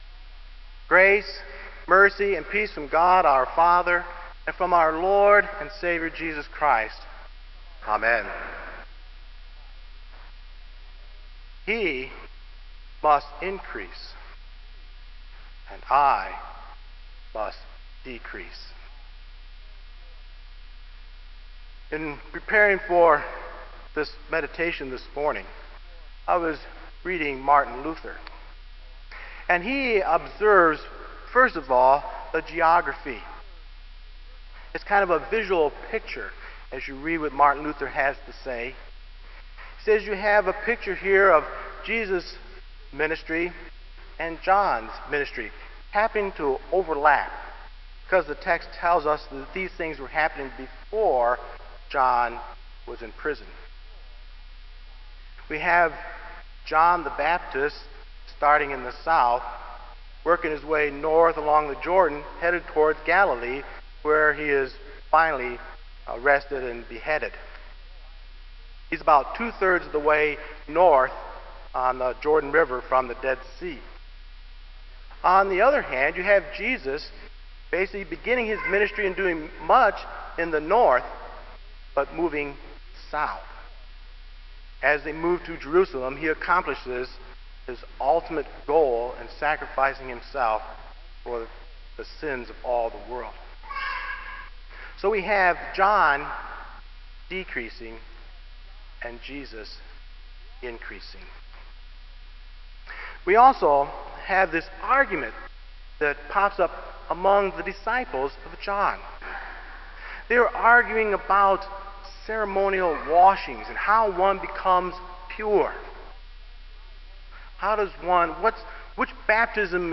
Kramer Chapel Sermon - January 16, 2002